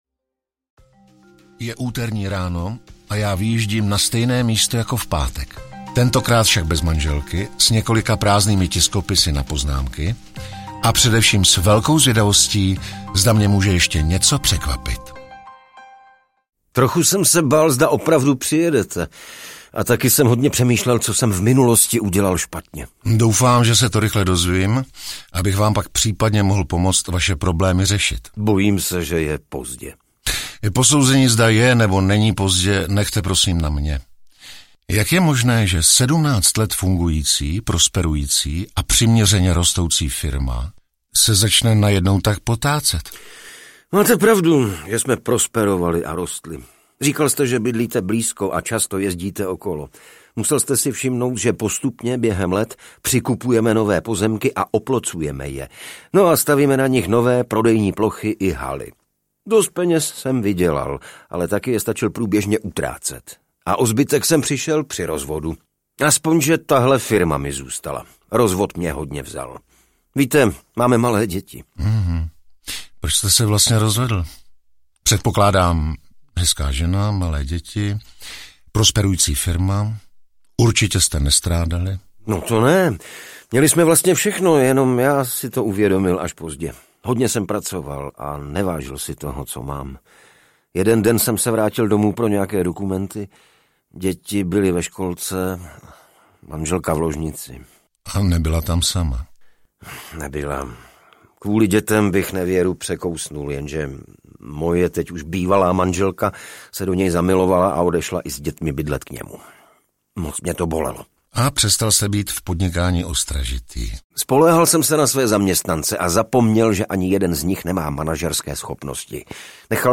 Audiokniha
Spojení zajímavých příběhů s hlasy známých českých herců podtrhuje atraktivitu celého projektu. Čtvrtý díl se odehrává v prodejně stavebního materiálu. Nevěrná manželka a následný rozvod byl málem příčinou krachu zavedené firmy.